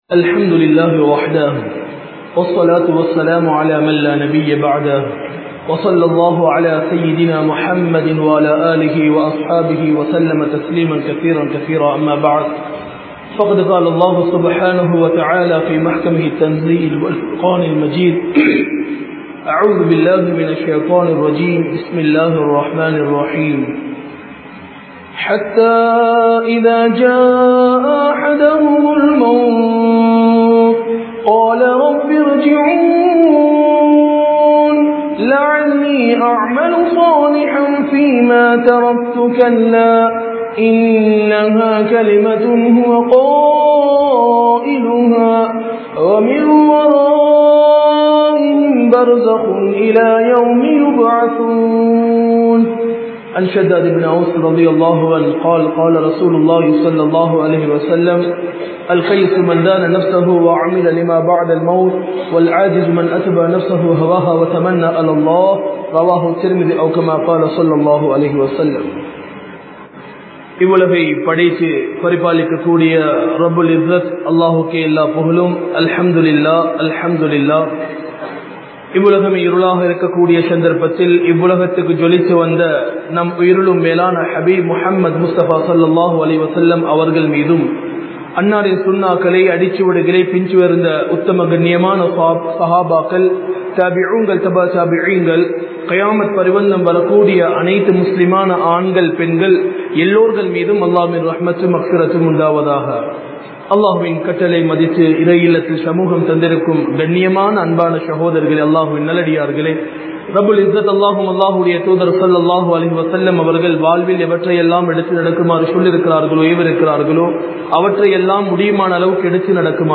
Marakka Mudiyatha Maranam (மறக்க முடியாத மரணம்) | Audio Bayans | All Ceylon Muslim Youth Community | Addalaichenai
Grand Jumua Masjidh